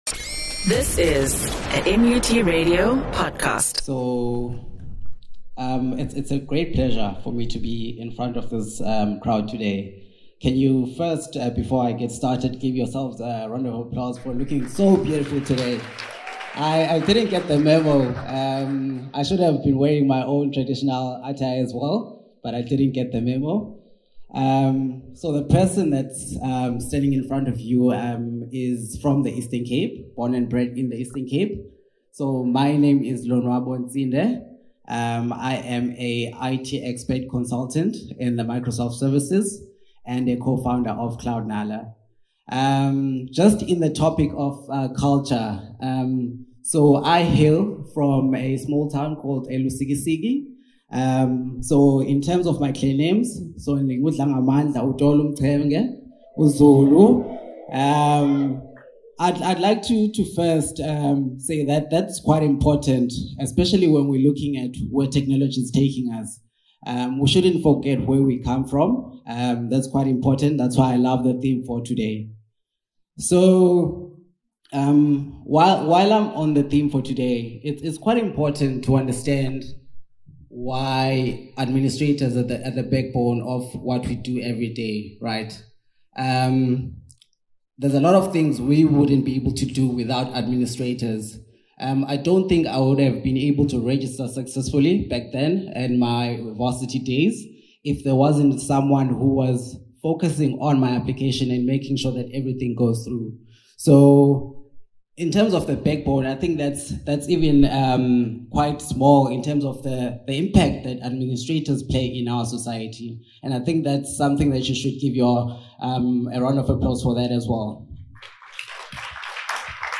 EAC 2025 Presentation